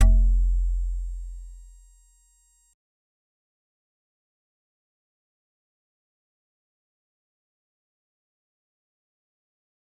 G_Musicbox-F1-pp.wav